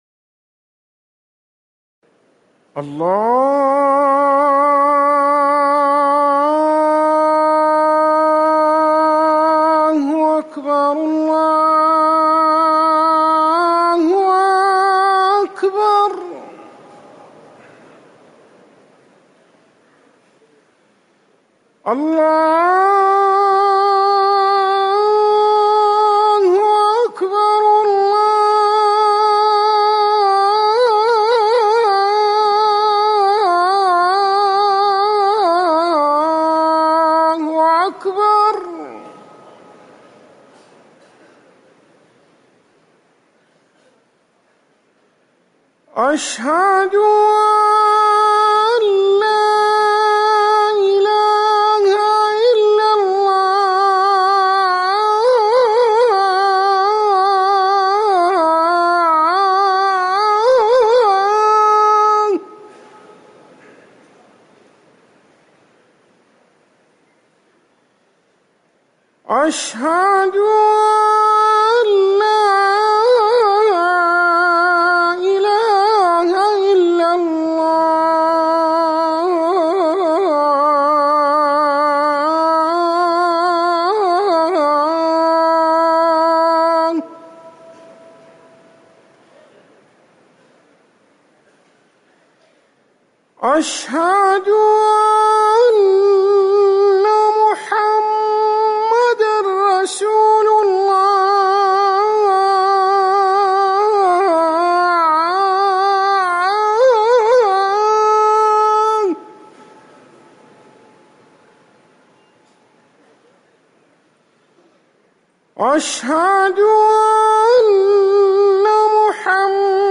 أذان الظهر - الموقع الرسمي لرئاسة الشؤون الدينية بالمسجد النبوي والمسجد الحرام
تاريخ النشر ٤ محرم ١٤٤١ هـ المكان: المسجد النبوي الشيخ